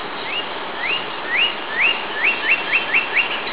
Northern Cardinal
Roselle, NJ, 6/20/99, male (28kb) "whoit", 4 introductory notes and 5 whoits